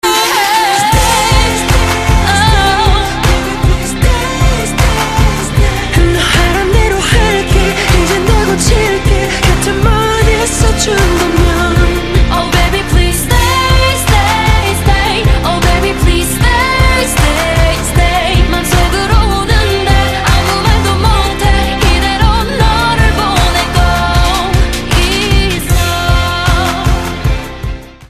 分类: MP3铃声
简单轻松可爱款的短信息铃音 爱要讨好